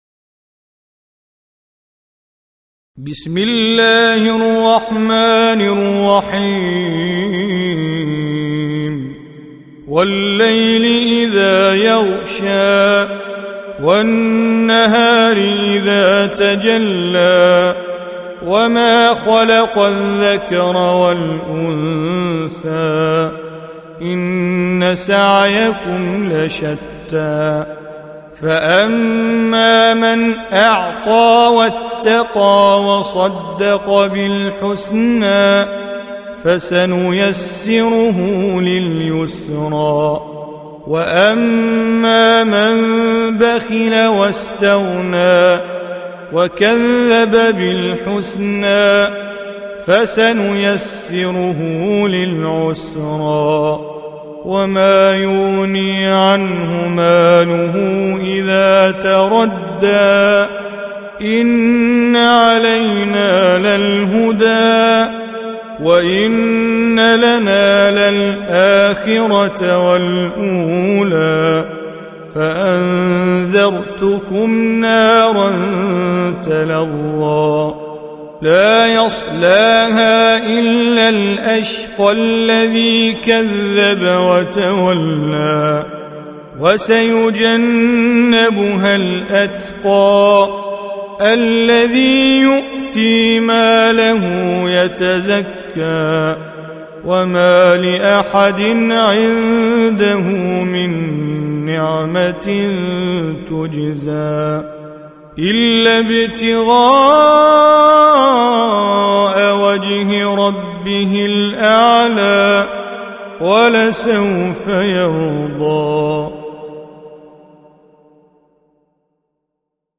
Surah Repeating تكرار السورة Download Surah حمّل السورة Reciting Mujawwadah Audio for 92. Surah Al-Lail سورة الليل N.B *Surah Includes Al-Basmalah Reciters Sequents تتابع التلاوات Reciters Repeats تكرار التلاوات